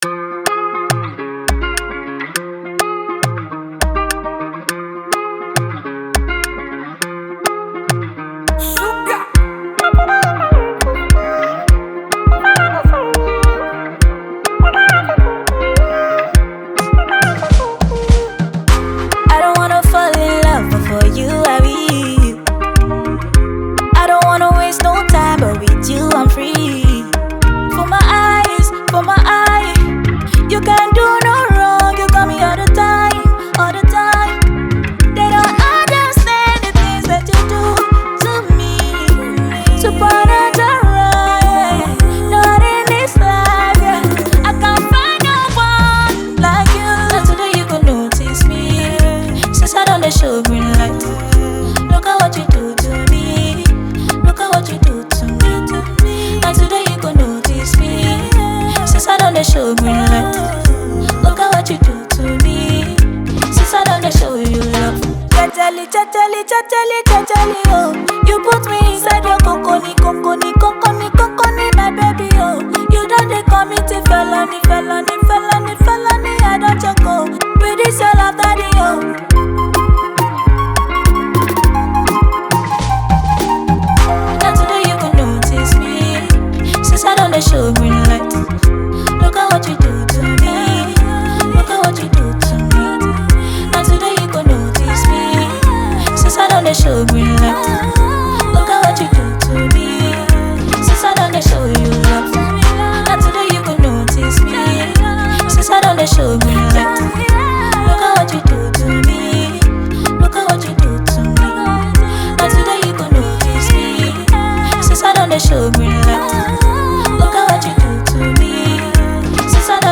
Celebrated Nigerian female singer and songwriter